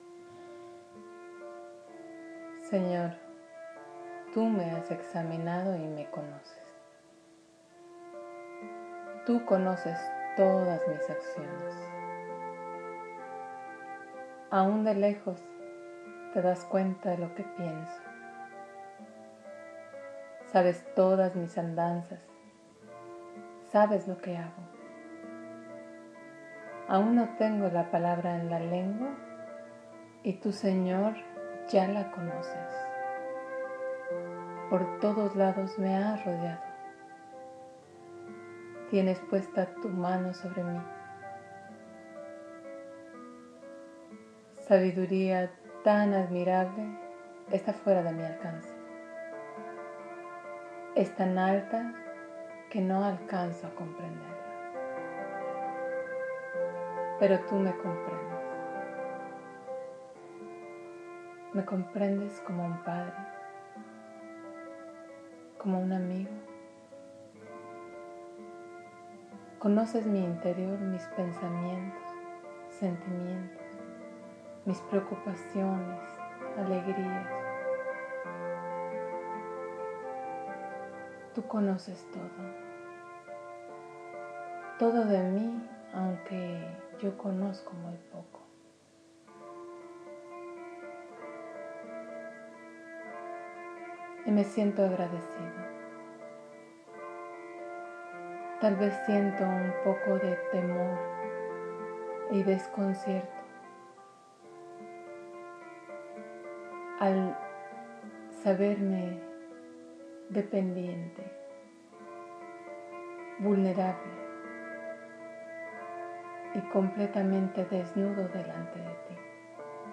Lectura meditada